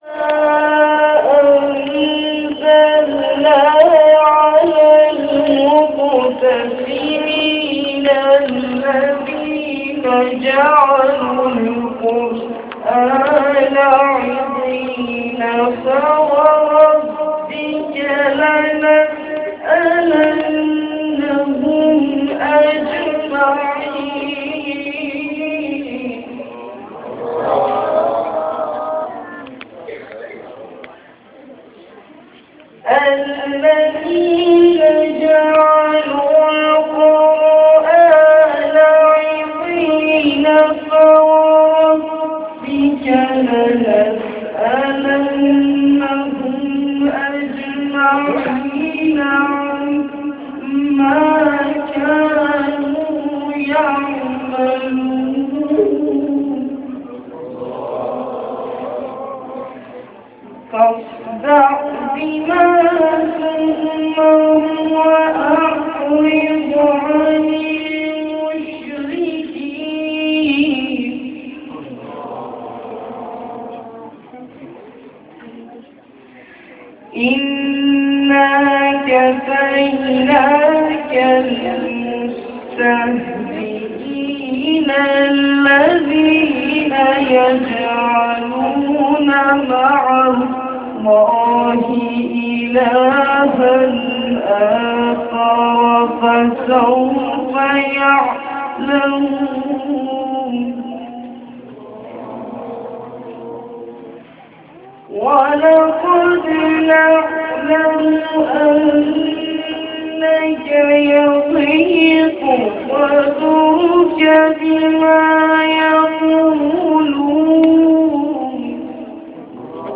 سخنرانی7.amr